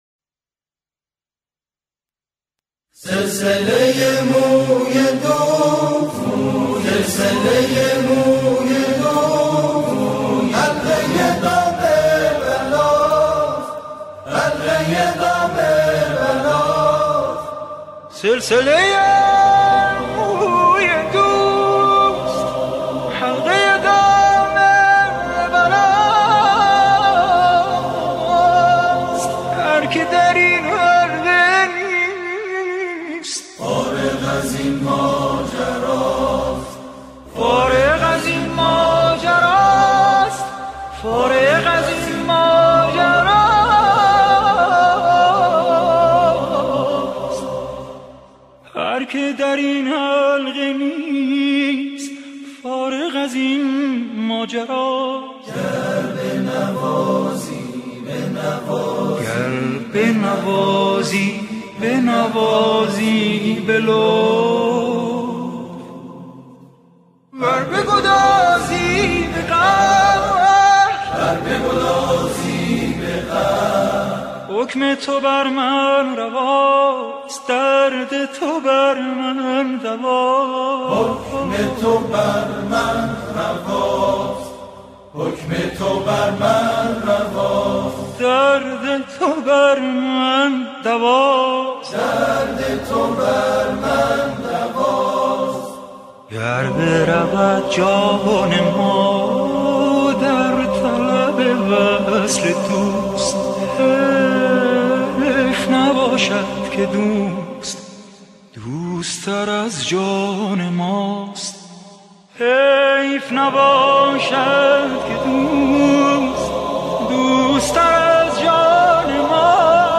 آکاپلا
با صدای گروهی از جمعخوانان و به صورت آکاپلا اجرا شده